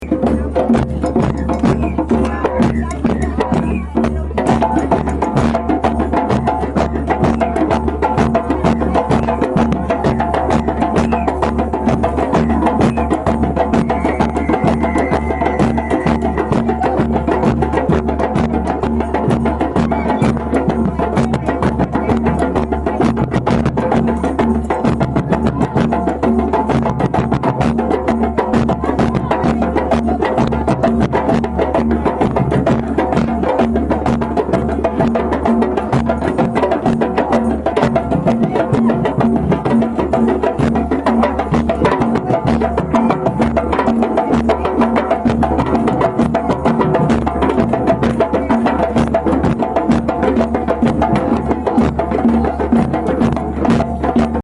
Here are therapeutic song & drumming samples:
Laguna Canyon Drum 50secs.mp3